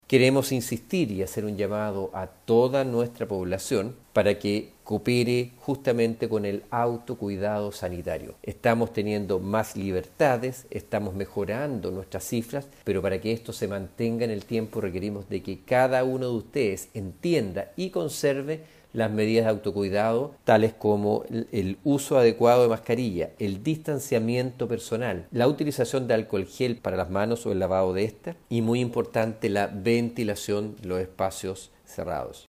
La autoridad de salud, además realizó un nuevo llamado a la población al autocuidado: